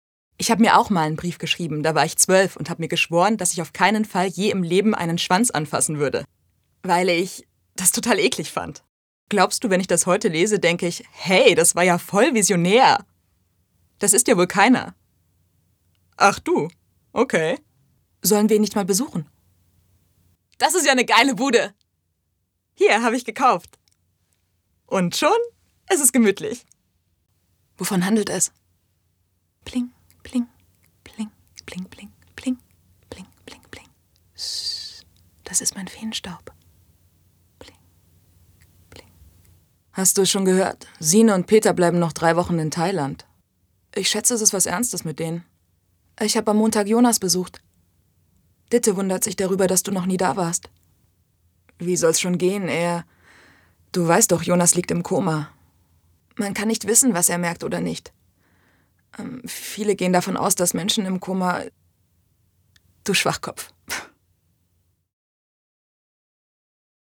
Stimmproben
Synchron - variabel